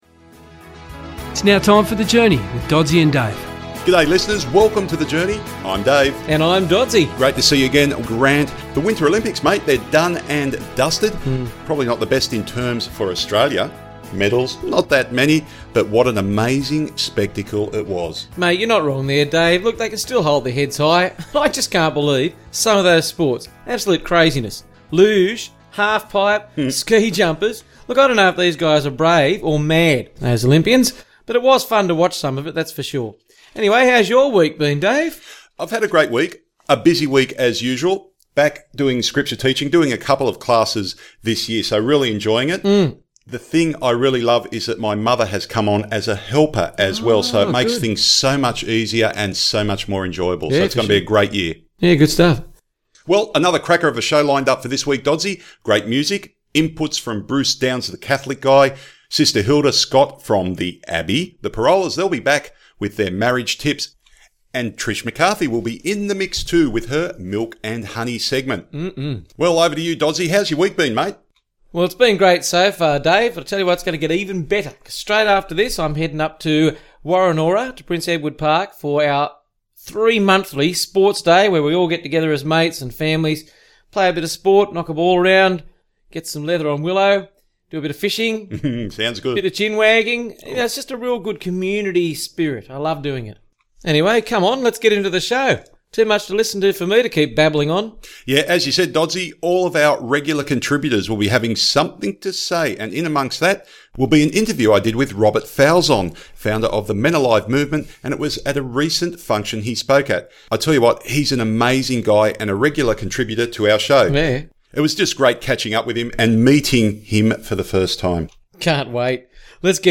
"The Journey" Catholic Radio is a weekly Catholic radio show produced by the Diocese of Wollongong and aired on Pulse 94.1FM along the Illawarra and South Coast on Sundays from 11am-12pm and repeated on Wednesday Evenings from 10pm-11pm.
There are also regular interviews highlighting the good things being done in the Church and its agencies.